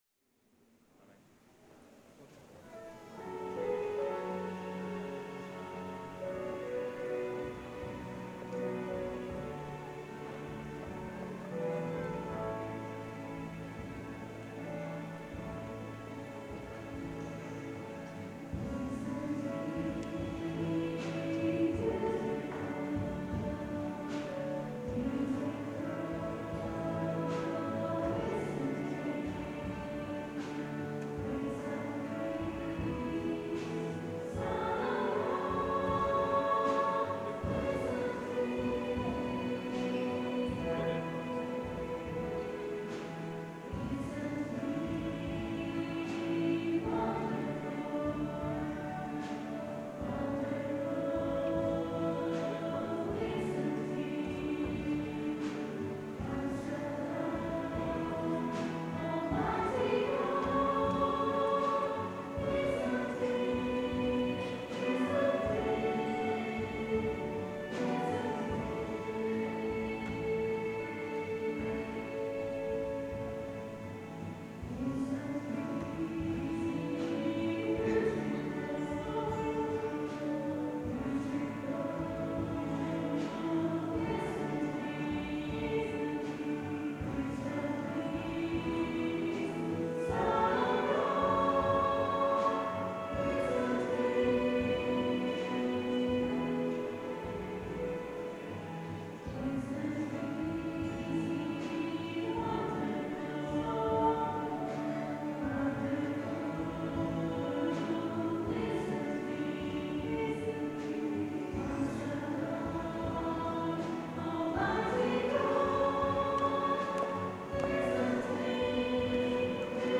Recorded on Sony Minidisc in digital stereo at Easter Sunday mass at 10am on 23rd March 2008.